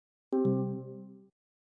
Звук беспроводных наушников Apple AirPods Pro 2 и других в mp3 для монтажа
2. И сразу звук отключения наушников AirPods от телефона
airpods-otkl.mp3